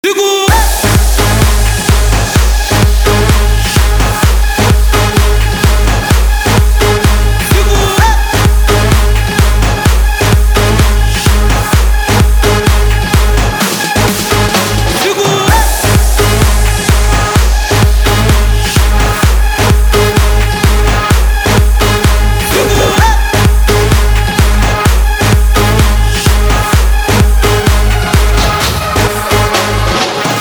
• Качество: 320, Stereo
громкие
Стиль: Electro House